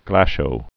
(glăshō), Sheldon Lee Born 1932.